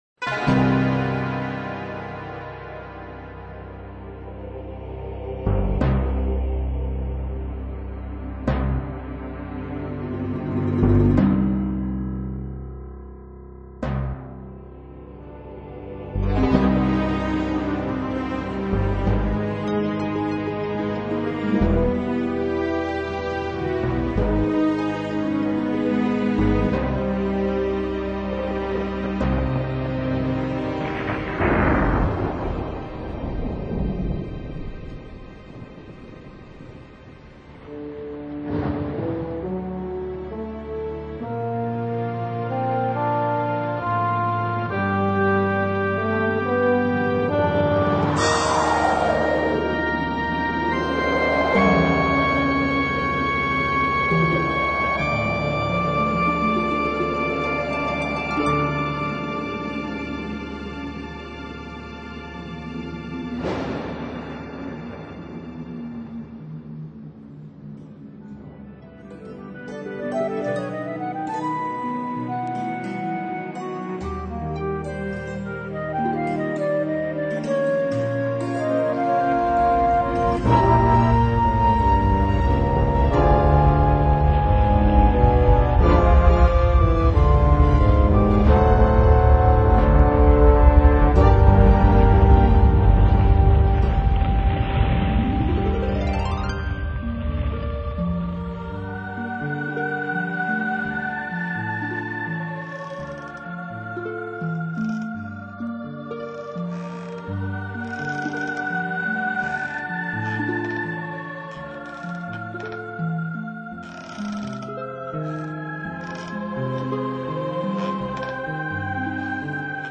banda sonora